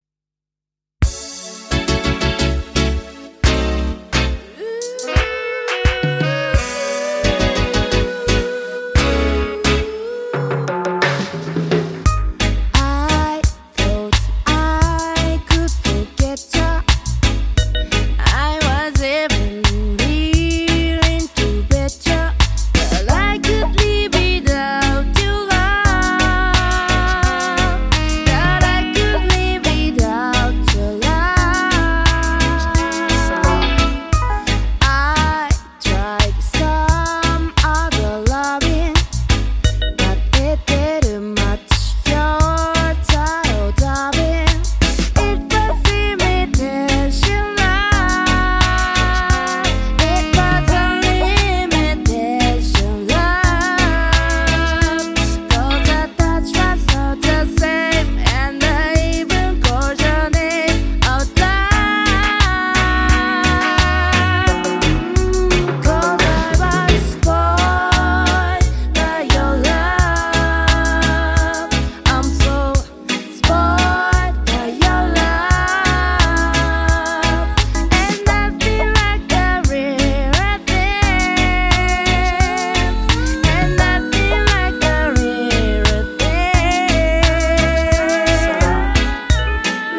形式 : 7inch
スモーキーなヴォイスがアンニュイな甘すぎない1曲。
# REGGAE / SKA / DUB# LOVERS